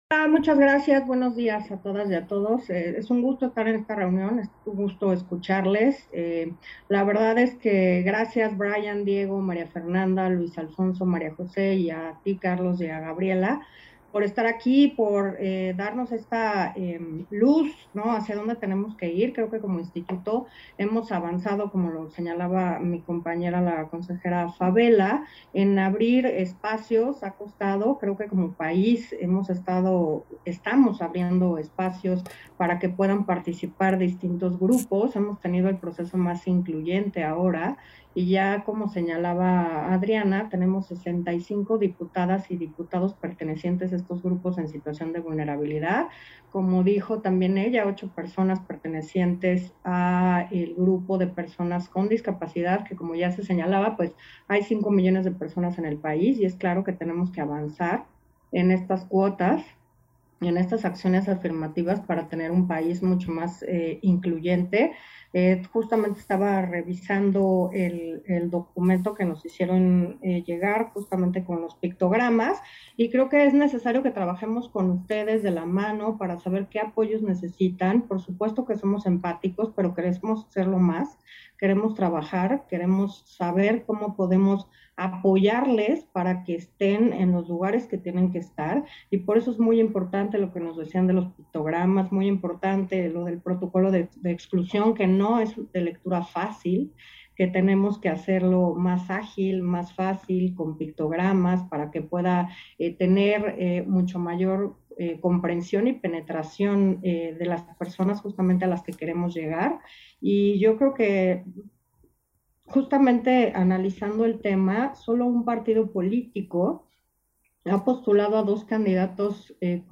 Intervenciones de Consejeras y Consejeros del INE, en la reunión